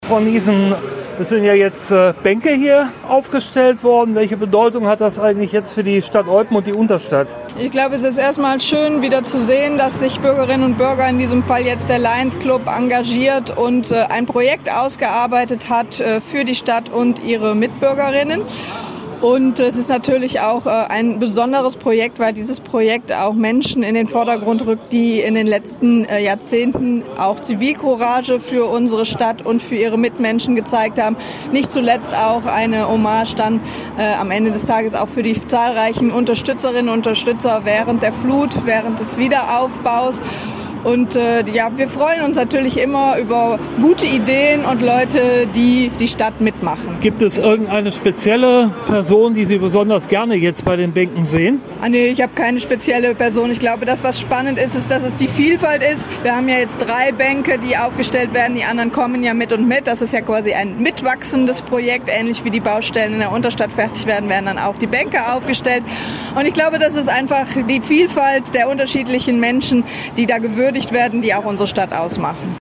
sprach mit Eupens Bürgermeisterin Claudia Niessen.